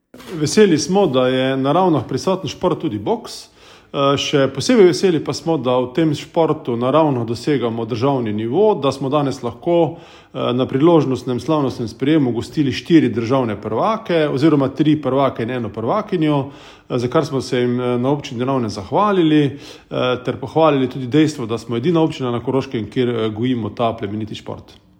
Več pa župan Tomaž Rožen.